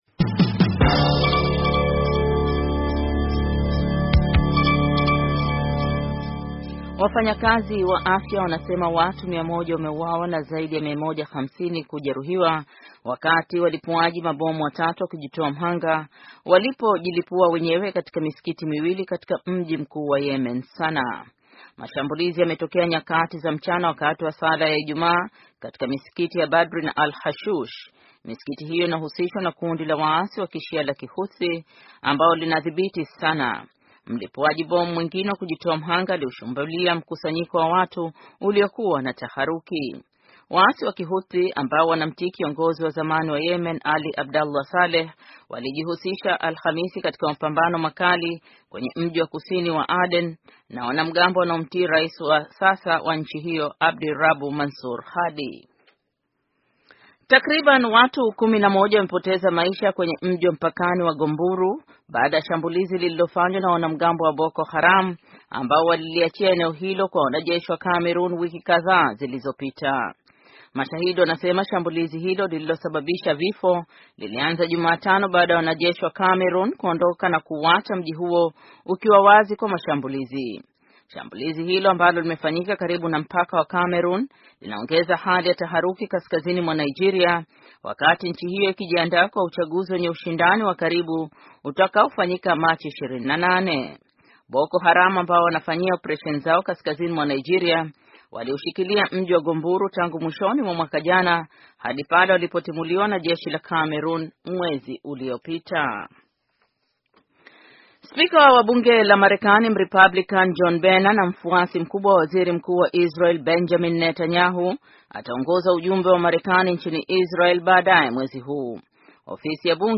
Taarifa ya habari - 6:14